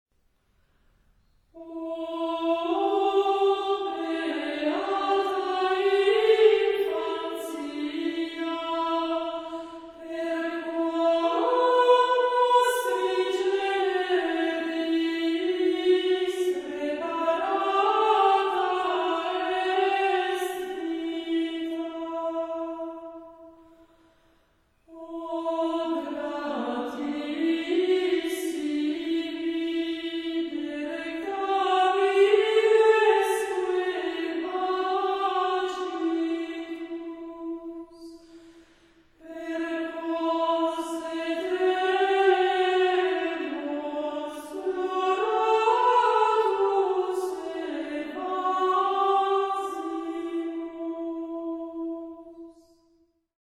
Исполнитель — вокальный ансамбль "Stirps Iesse".
Запись сделана в церкви Пресвятой Богородицы Кармельской в Ролетто (Италия) в 1998 г.